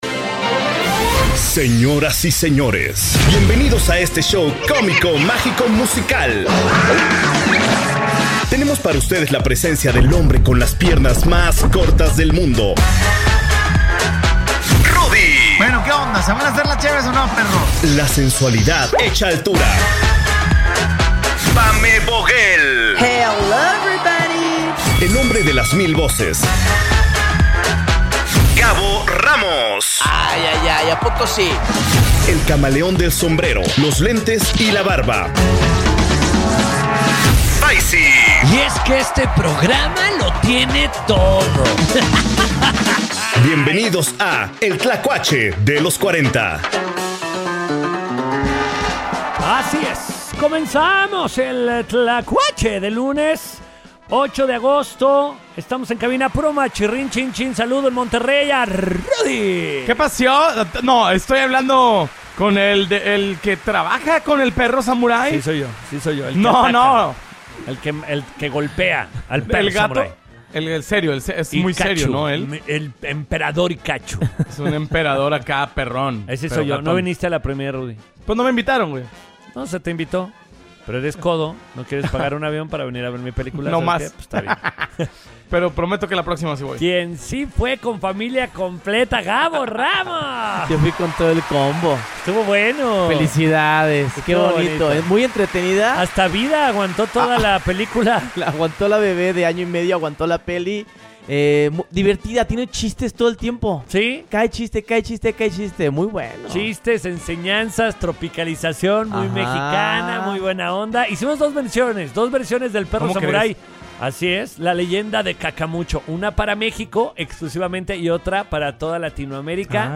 Pedro Sampaio en vivo desde Diablopolis…